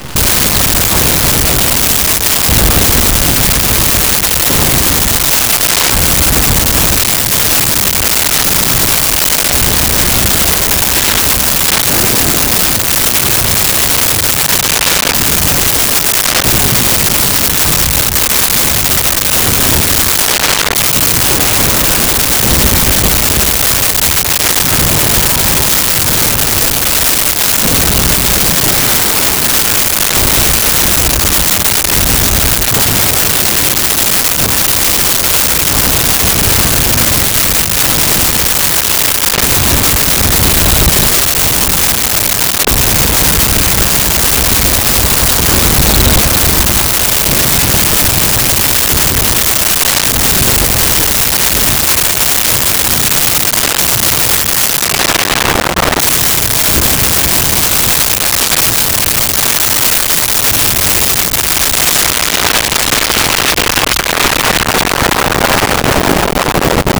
Explosions Large
Explosions Large.wav